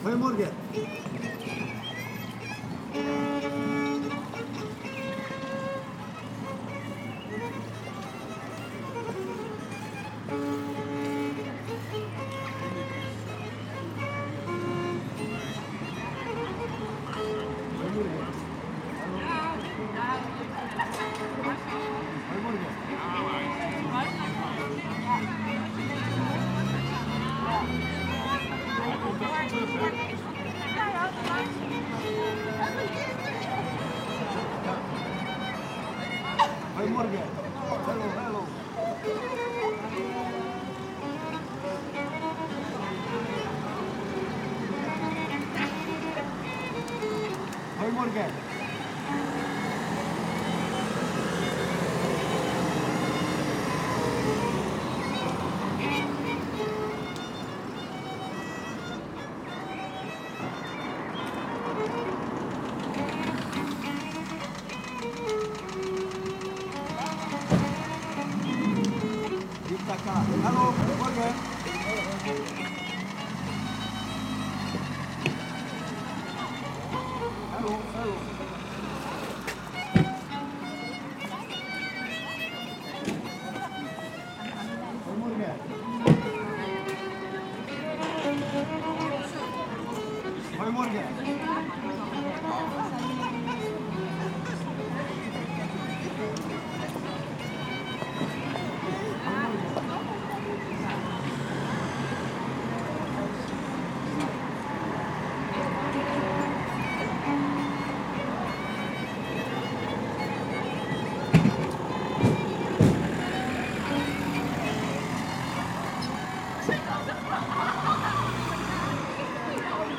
friendly street musician 160409_05
ambiance ambience ambient atmos atmosphere birds cars chatting sound effect free sound royalty free Nature